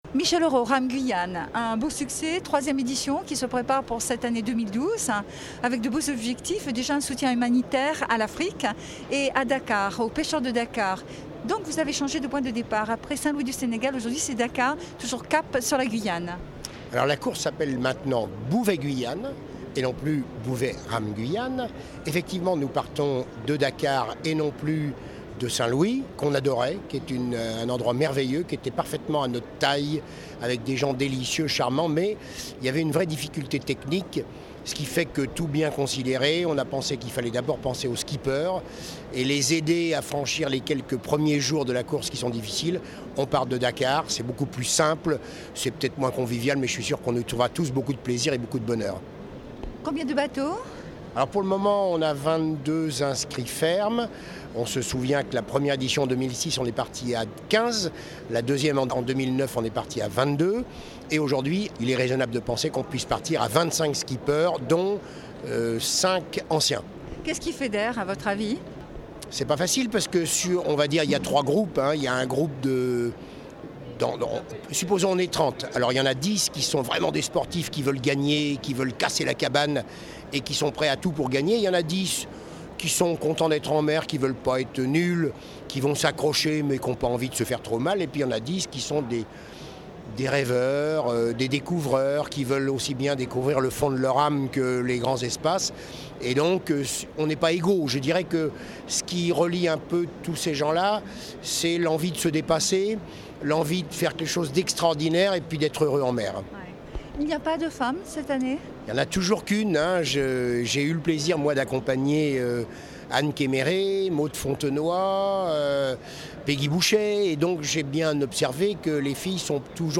ITW
au Nautic de Paris